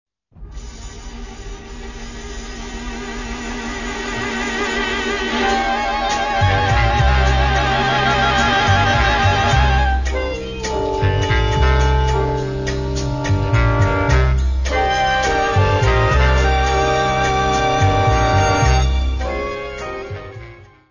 : stereo; 12 cm